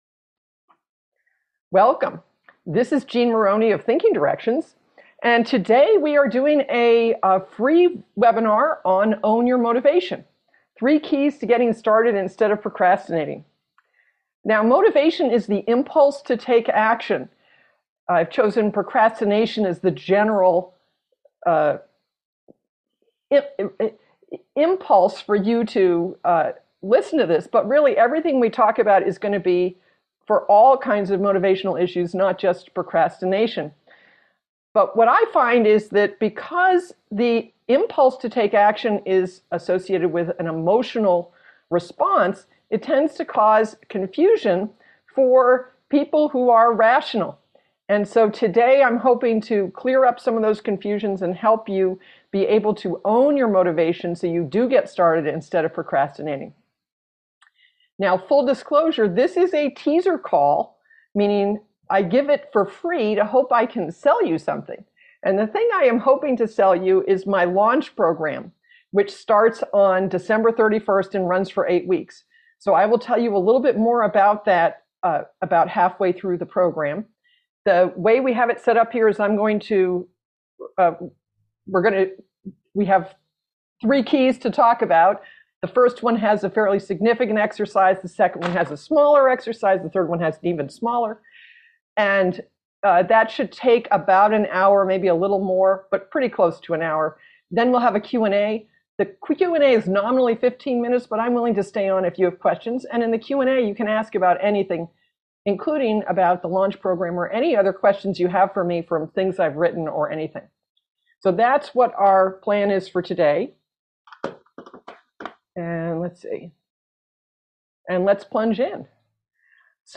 Thank you for registering for this free 1-hour webinar.